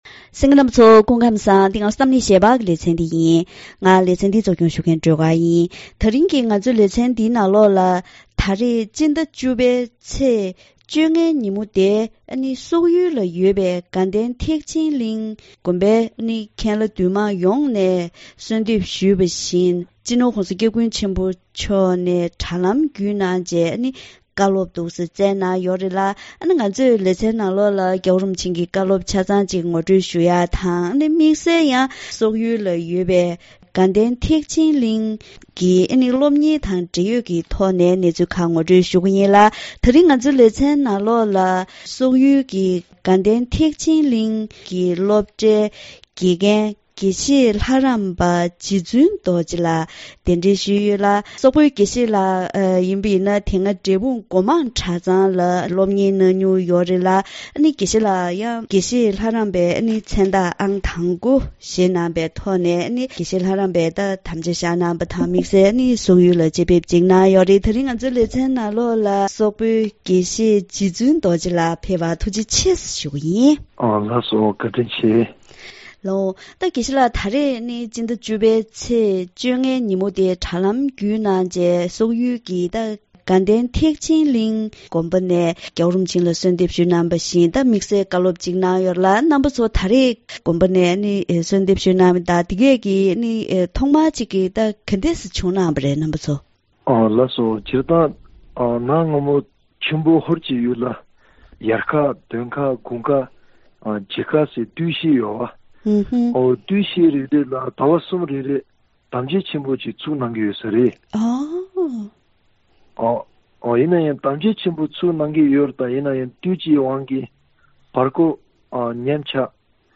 ༧གོང་ས་མཆོག་ནས་དྲ་ལམ་བརྒྱུད་སོག་ཡུལ་གྱི་དགའ་ལྡན་དགོན་པར་བཀའ་སློབ་གནང་པ།